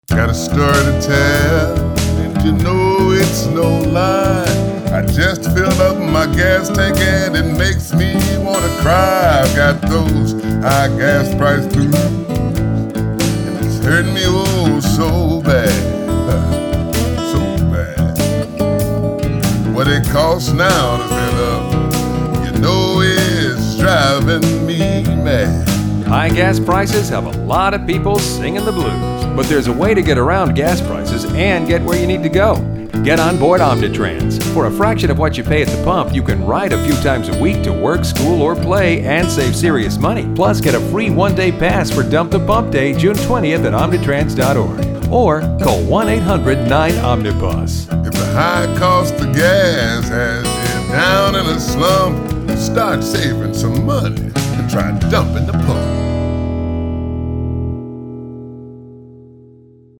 2012 AdWheel Awards (American Public Transportation Association) First Place Award Electronic Media – Radio, single spot Omnitrans, Gas Price Blues.